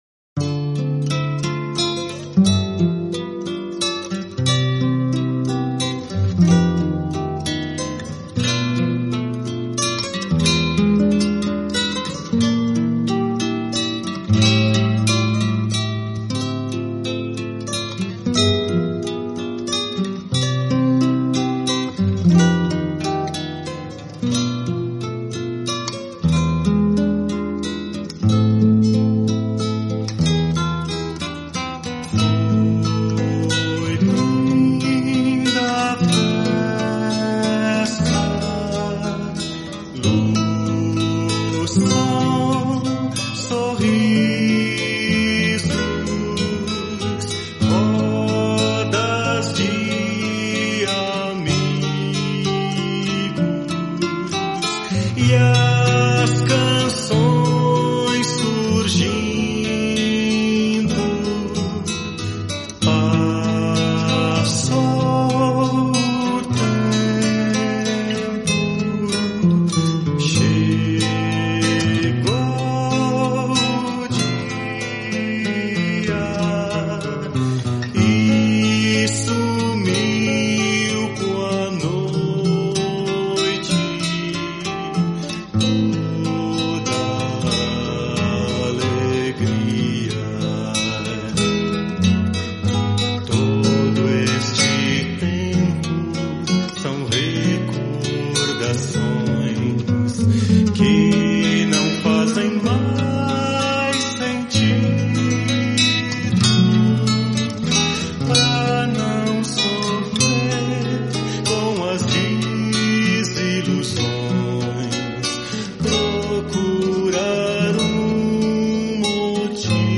Sermões Escolhidos - Plano 3 - Dia 5 de 6